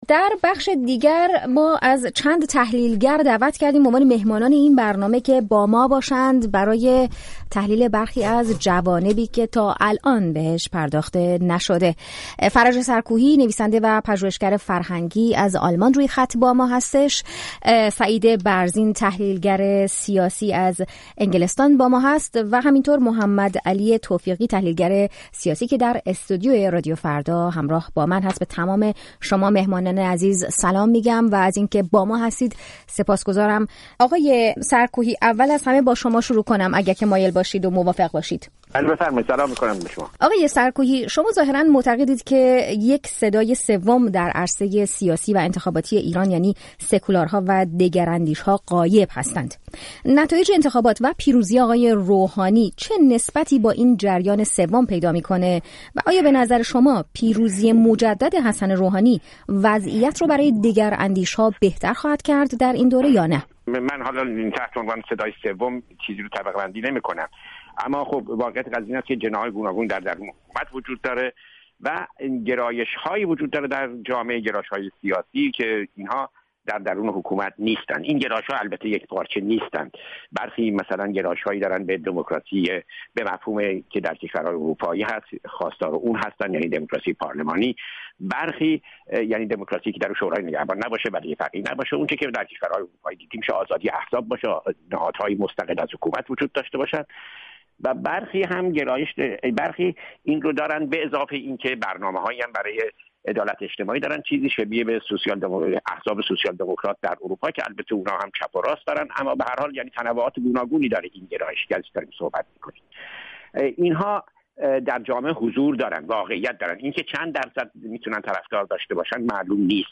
رادیو فردا در میزگردی